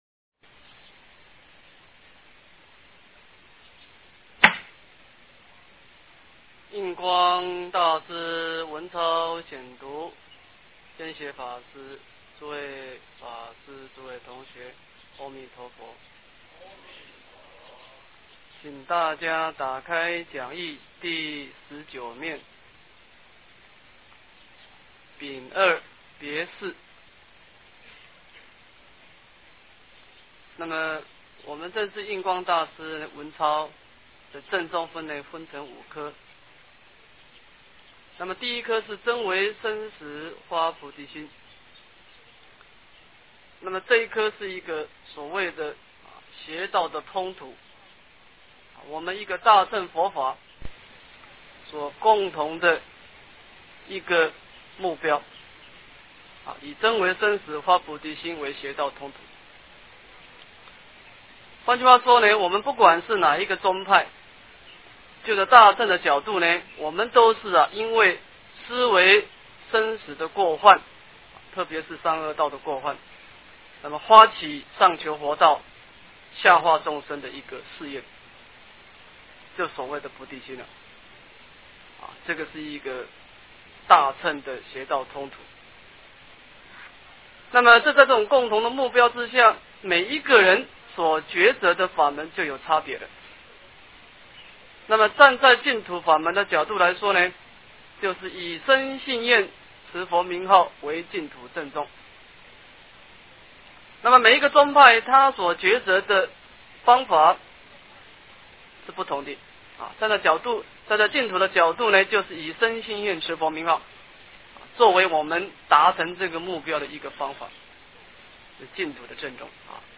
印光法师文钞09 - 诵经 - 云佛论坛